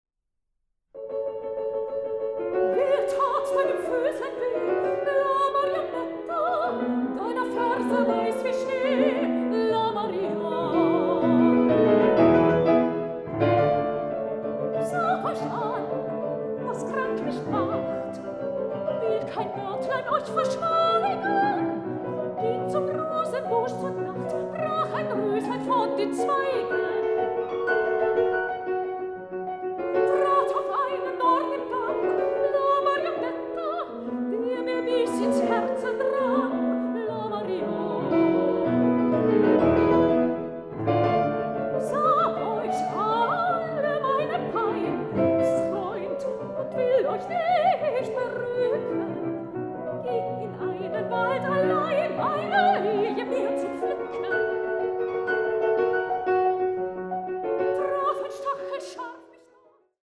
Sopran
Klavier
Aufnahme: Ackerscheune, Kulturstiftung Marienmünster, 2025